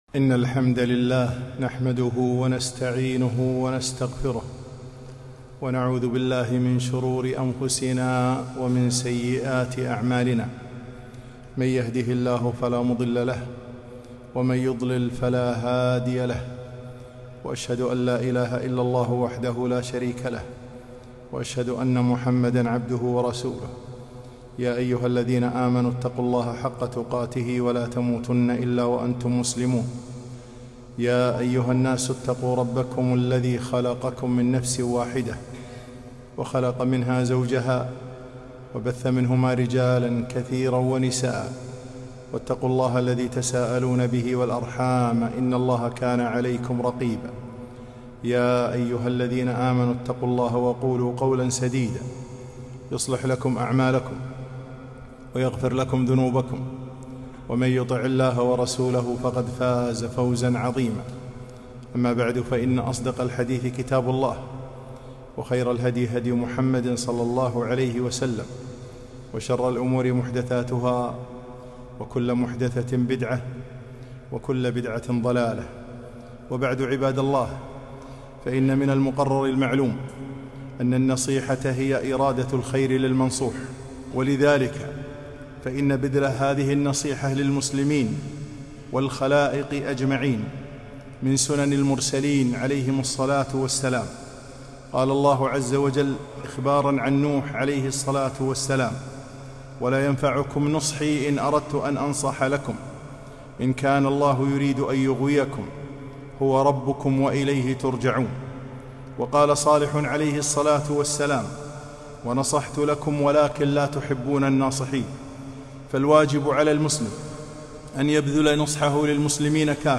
خطبة - النصيحة آداب وضوابط